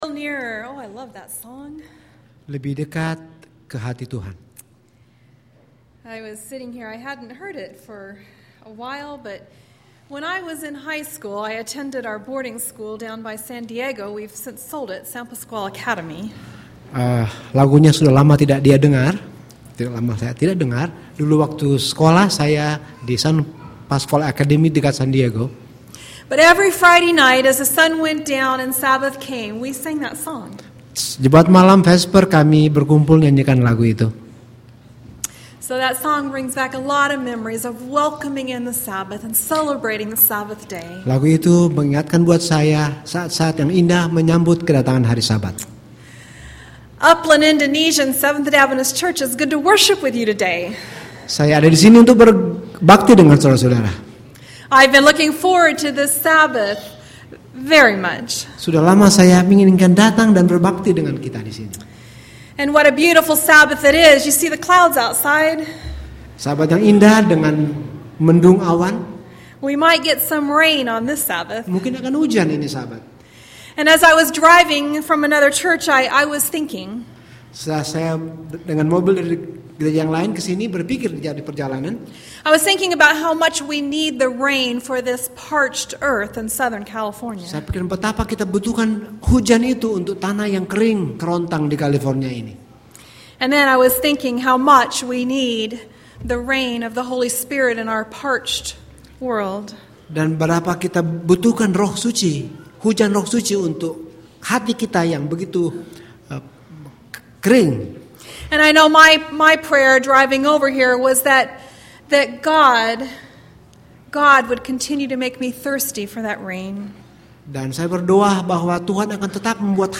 Media Sermons